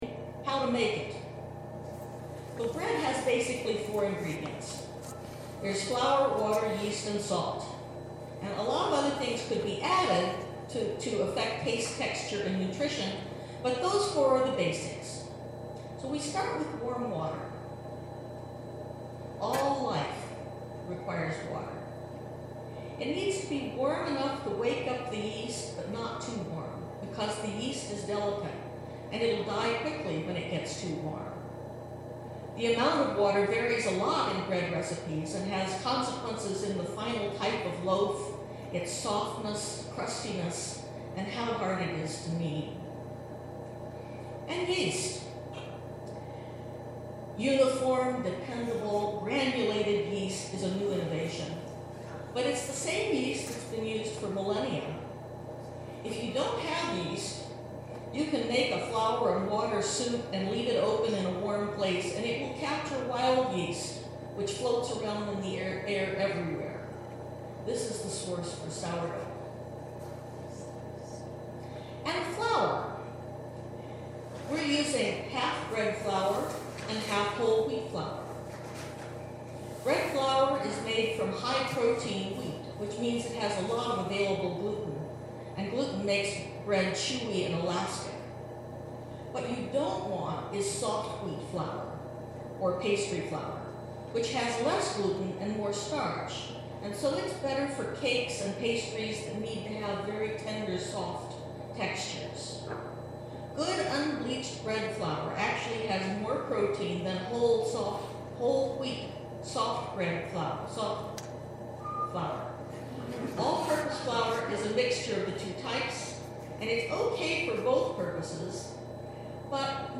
Posted in Sermons | Leave a Comment »